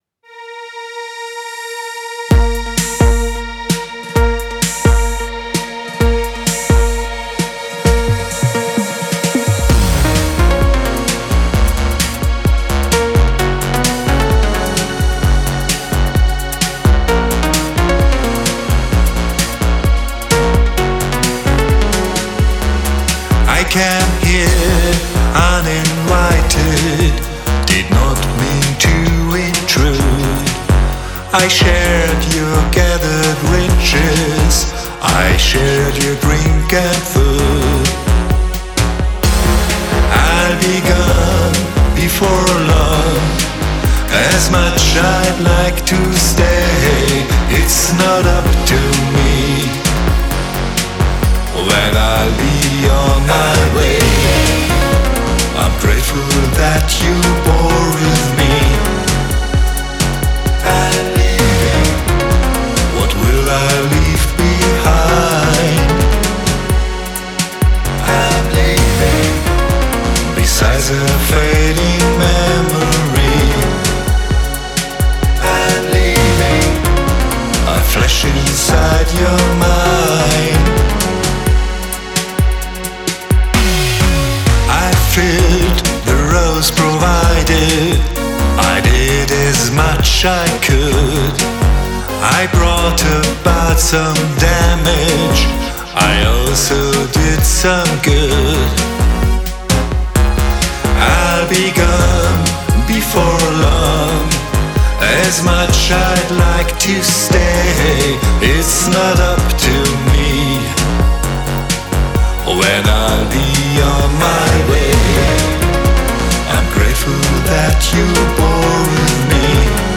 Is' Retro-Synthpop - nur zur Info ...
spaßeshalber hab ich dir mal einen quick-remaster (allerdings nur geringfügig leiser) gemacht.